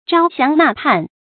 成語注音ㄓㄠ ㄒㄧㄤˊ ㄣㄚˋ ㄆㄢˋ
成語拼音zhāo xiáng nà pàn
發音讀音
招降納叛發音
成語正音降，不能讀作“jiànɡ”。